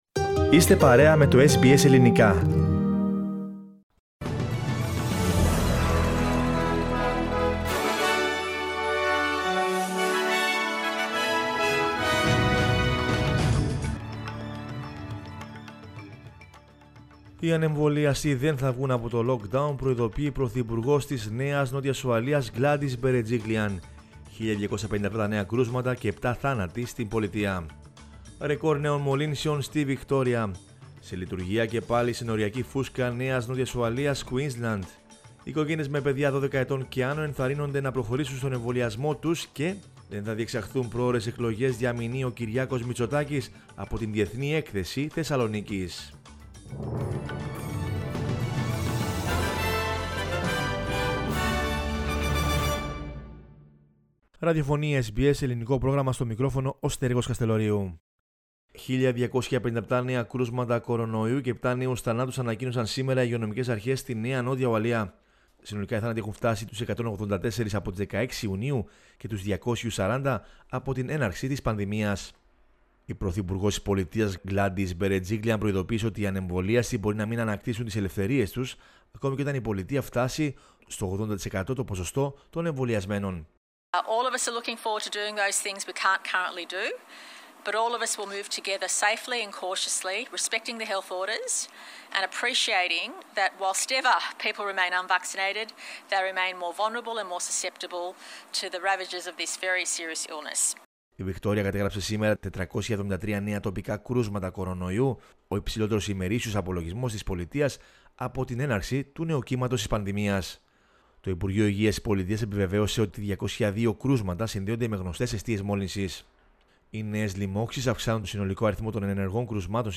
News in Greek from Australia, Greece, Cyprus and the world is the news bulletin of Monday 13 September 2021.